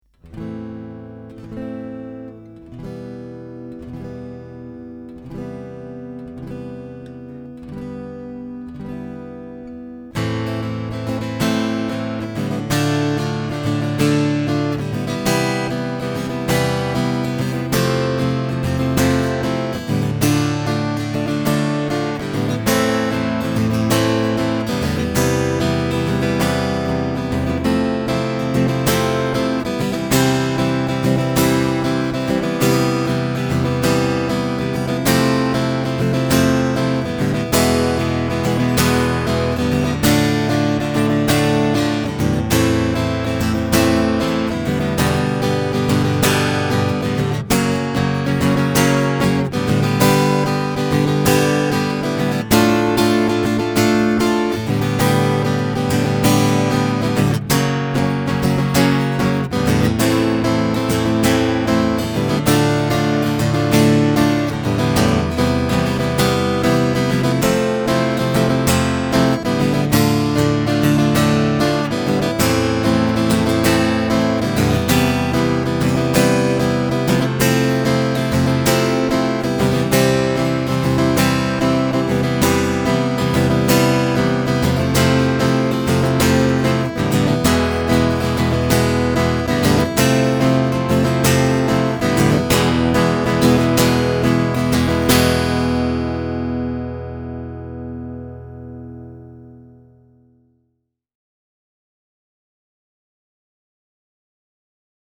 j'ai eu un peu de temps pour faire quelques enregistrements de ma belle Par contre, pas de micro statique, donc j'ai fais ça via la sortie jack pour le moment
Le morceau en accord est souvent à la limite de la saturation Il faudra que je refasse tout cela un peux mieux!
Accords :